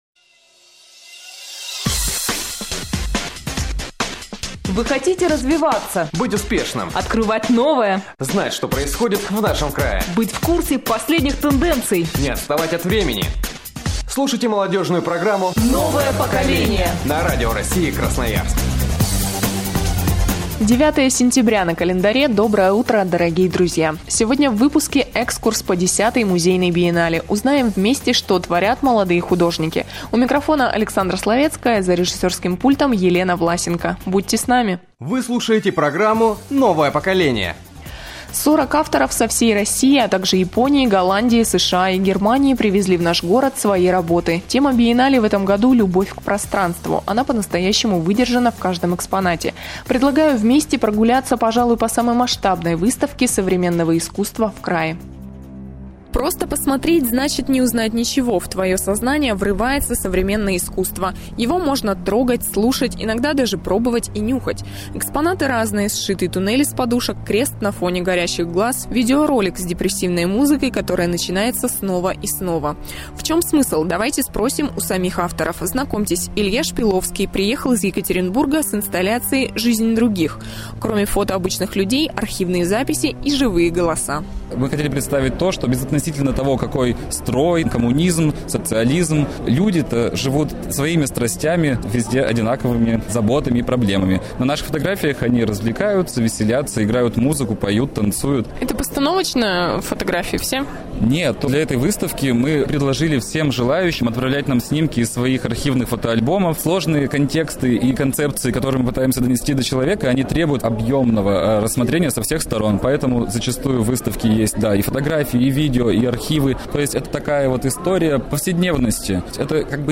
Репортаж биеннале